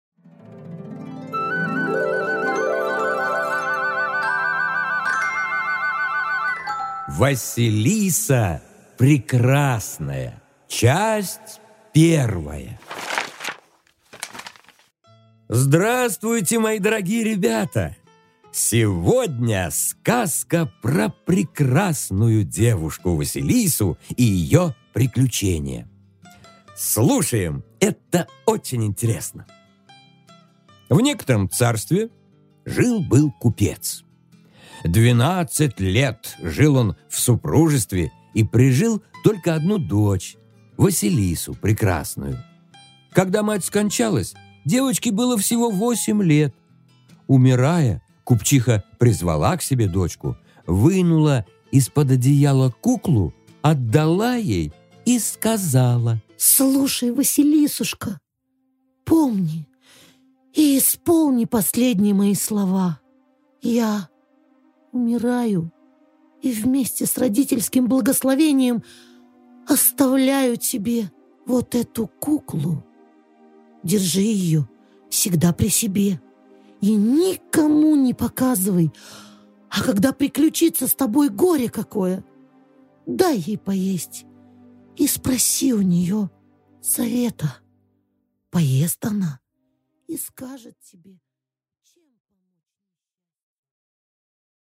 Аудиокнига Василиса Прекрасная